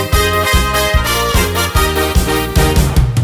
Gardetanzmusik